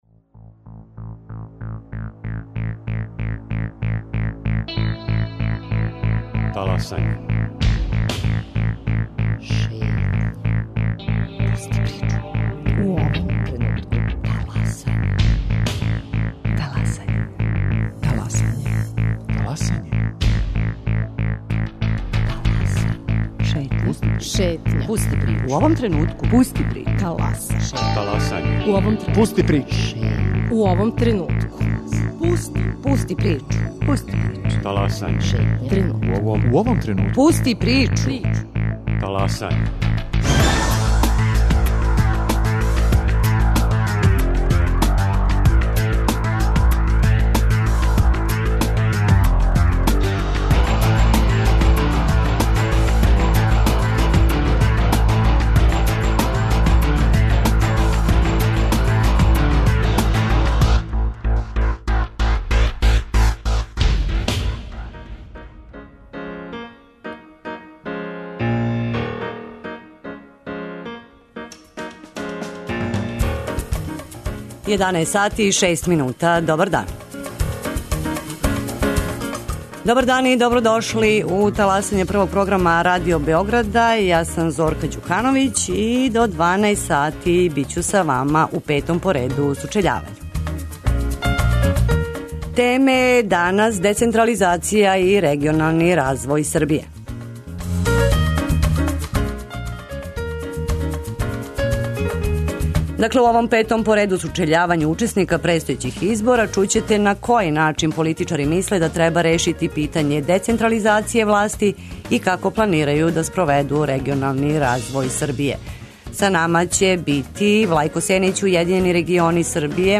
У петом по реду Сучељавању учесника предстојећих избора чућете на који начин политичари мисле да треба решити питање децентрализације власти и како планирају да спроведу регионални развој Србије.